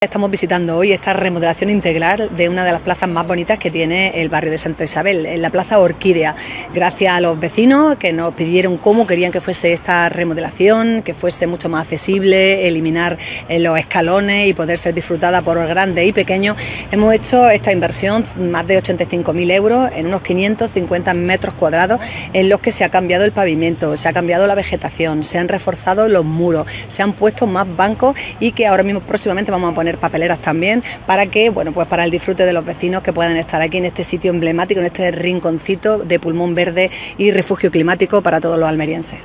ALCALDESA-PLAZA-ORQUIDEA.mp4.wav